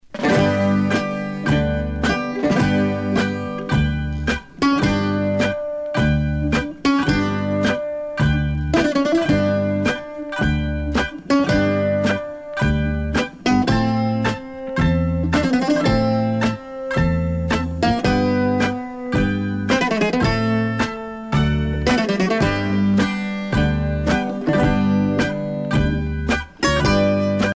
danse : sirtaki
Pièce musicale éditée